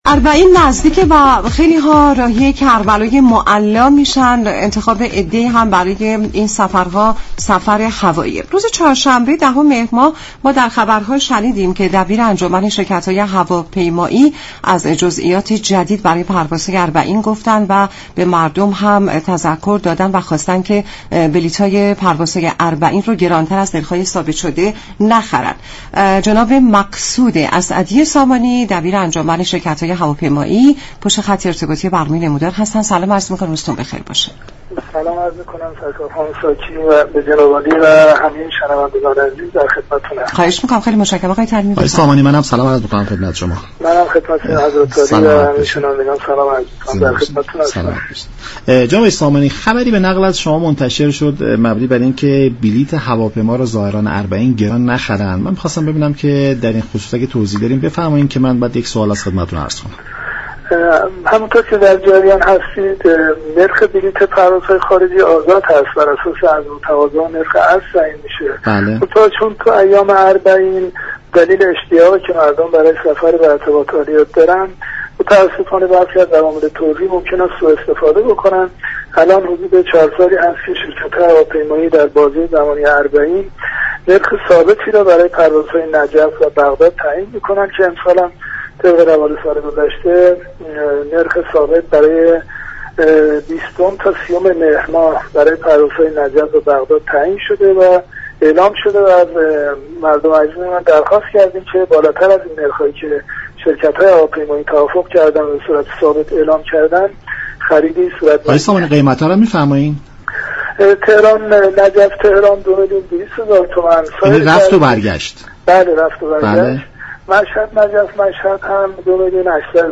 در گفت و گو با برنامه «نمودار» رادیو ایران